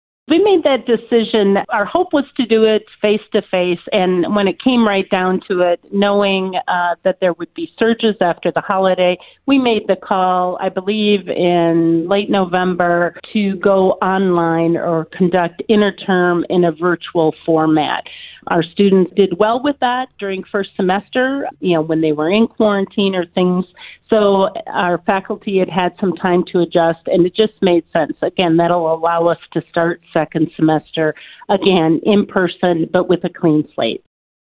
was a guest on the KHUB Morning Show on Thursday